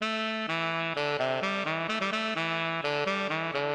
高音萨克斯音频片段
标签： 萨克斯管 样品 萨克斯 爵士乐
声道立体声